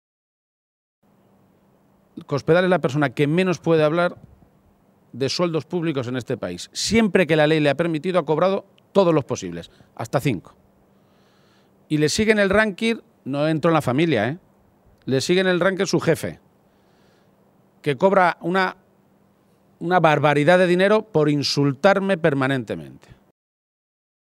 García-Page se pronunciaba de esta manera esta mañana, en Toledo, en una comparecencia ante los medios de comunicación durante la reunión que ha dirigido junto al secretario de organización federal del PSOE, César Luena.